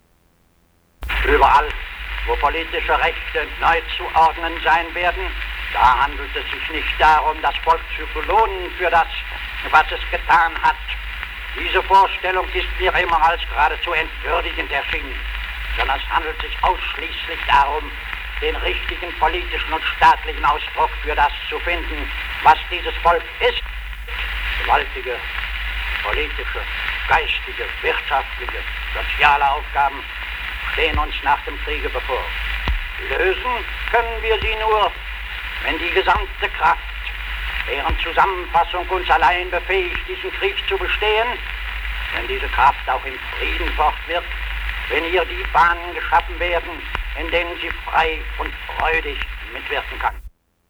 Excerpt of speech before the German Reichstag